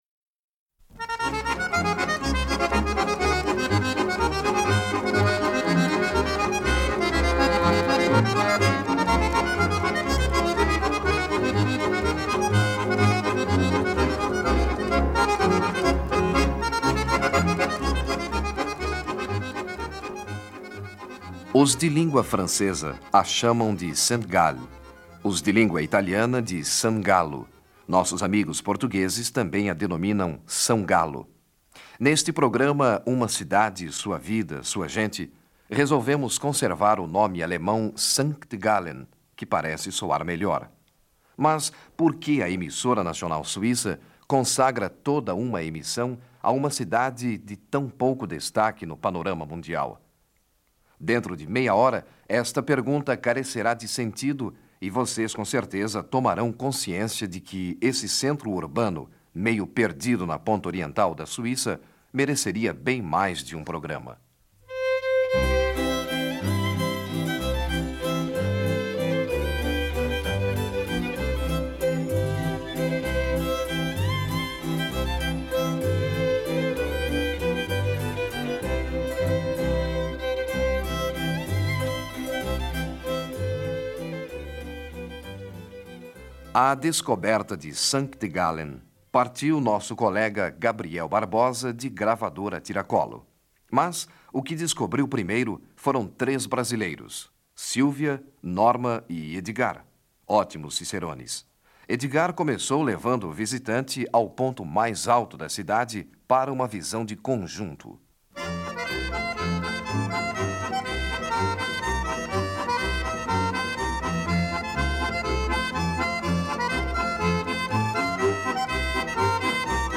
Reportagem de rádio sobre St. Gallen nos anos 1970